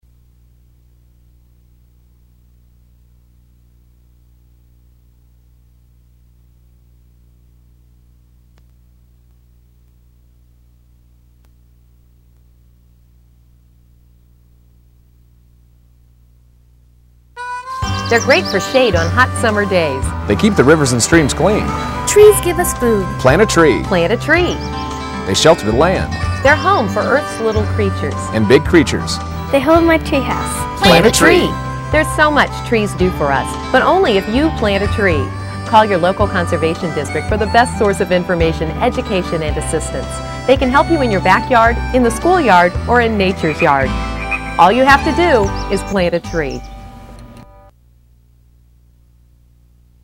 Advertising aids for Conservation District - TV and Radio ads for you to down load.